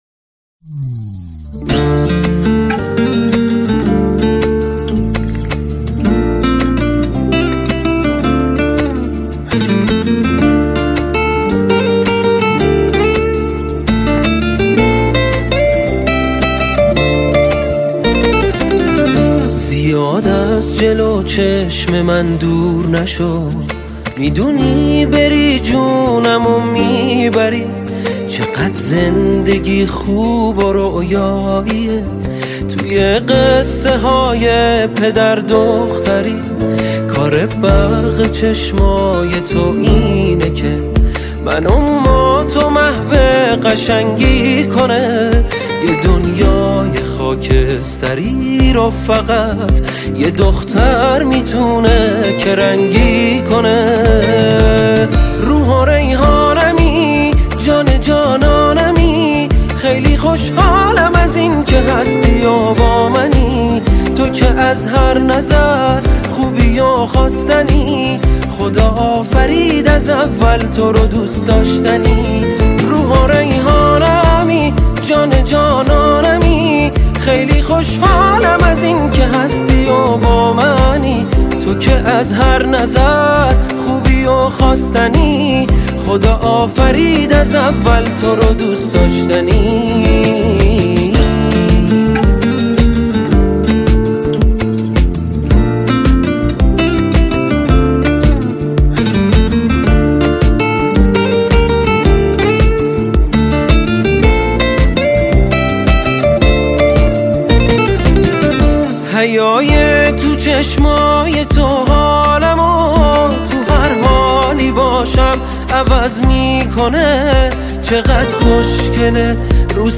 آهنگ روز دختر